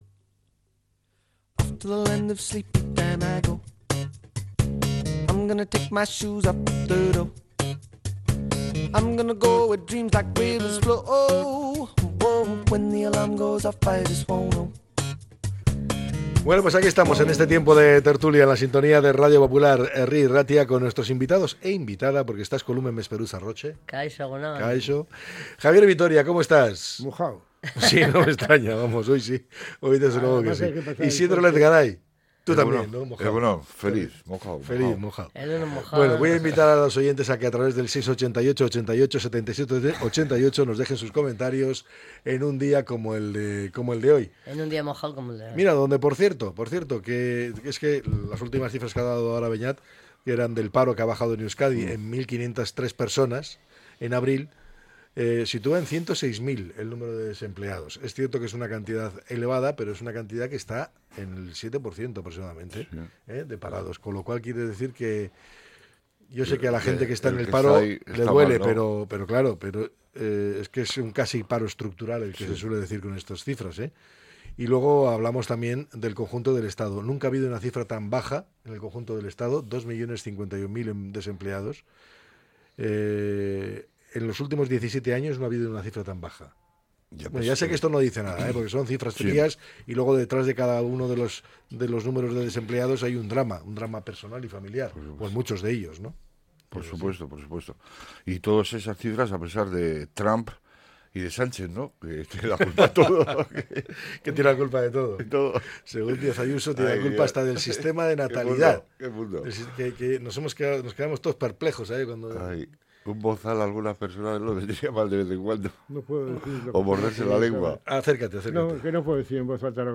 La tertulia 06-05-25.